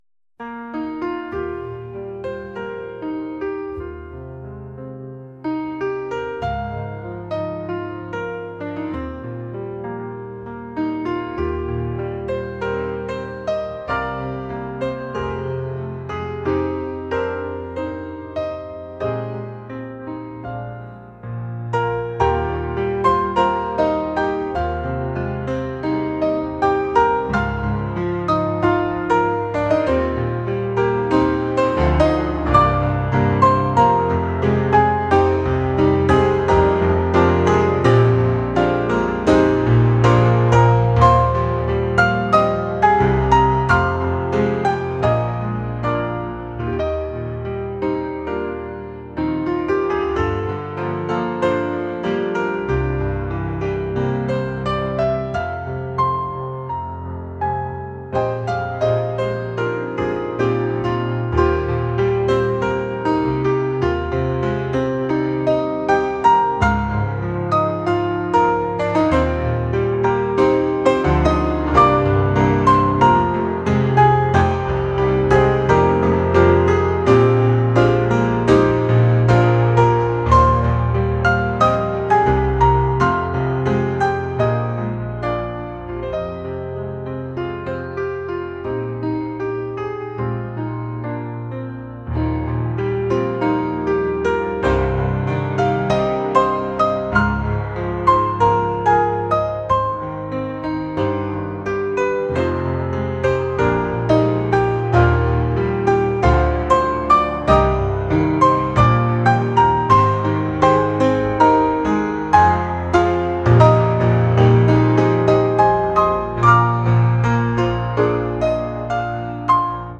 jazz | lounge | soul & rnb